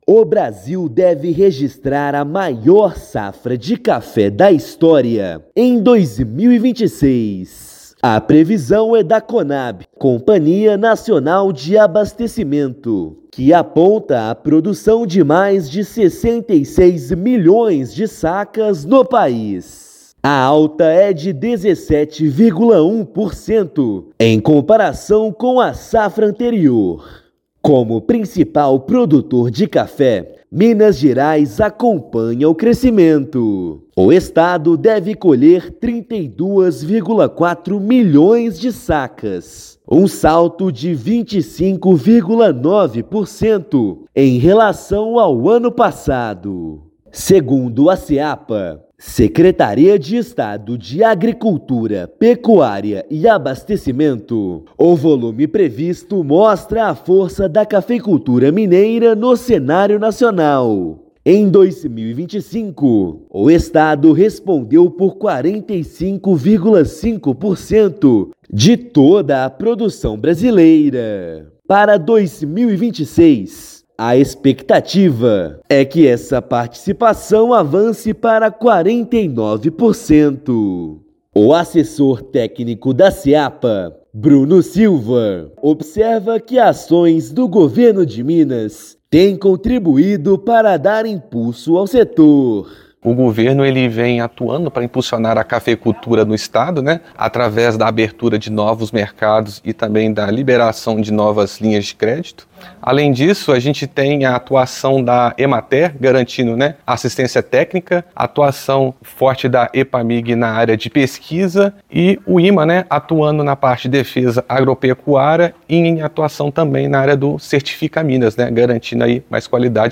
[RÁDIO] Café mineiro impulsiona produção nacional e projeta maior safra da história em 2026
Aumento expressivo é atribuído ao ano de bienalidade positiva e às boas condições climáticas, com chuvas na medida durante o desenvolvimento dos frutos. Ouça matéria de rádio.